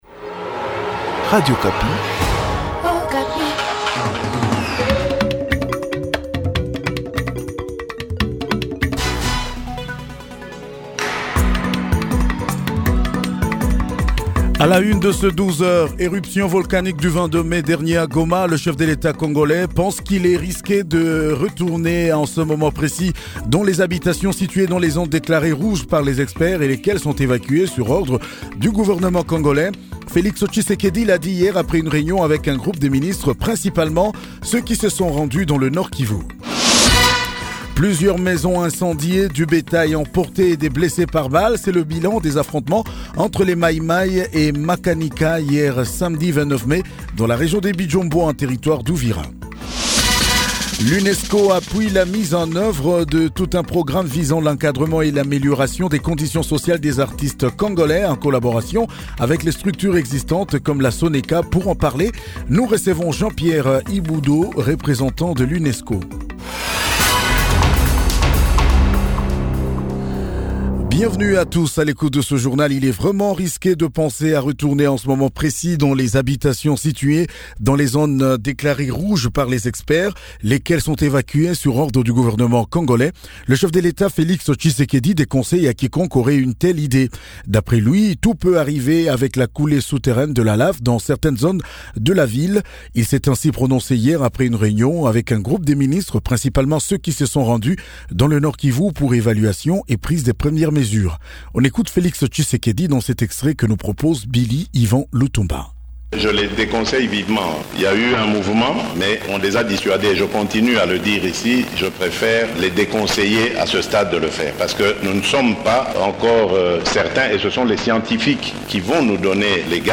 JOURNAL MIDI DU 30 MAI 2021
CONDUCTEUR DU JOURNAL 12H DIMANCHE 3005